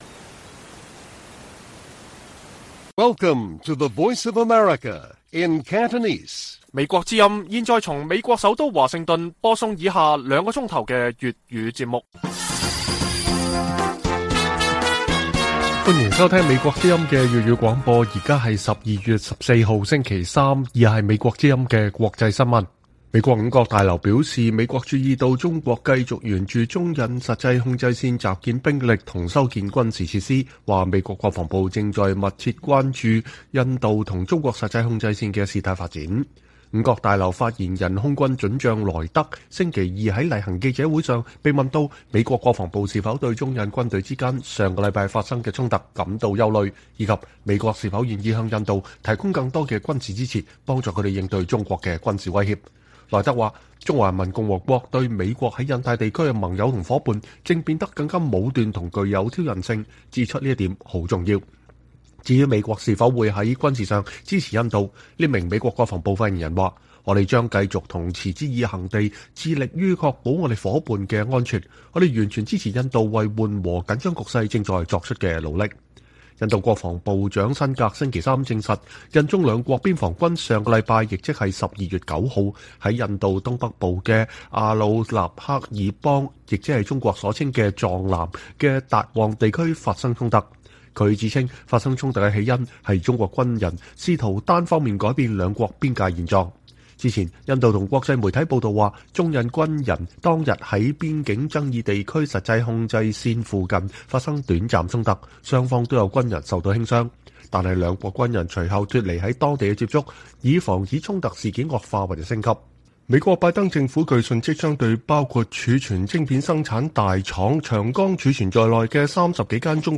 粵語新聞 晚上9-10點: 美國密切注視中印邊境事態發展